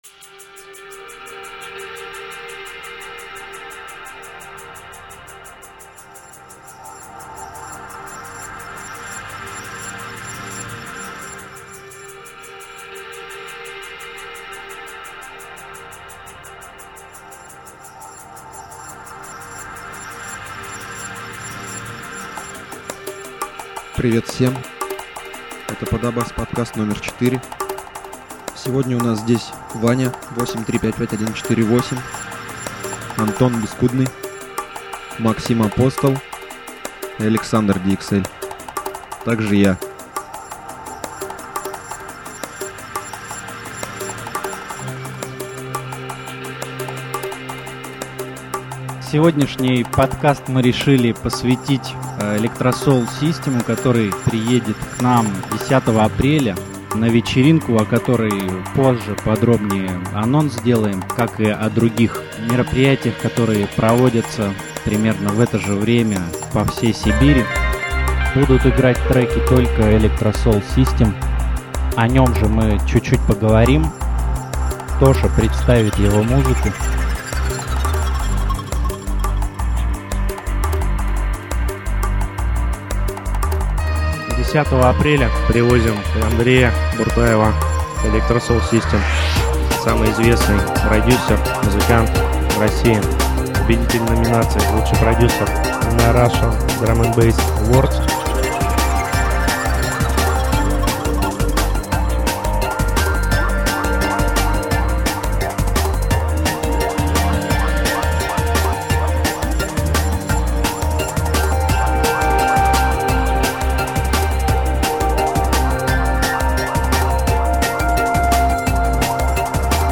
Liquidfunk Drum & Bass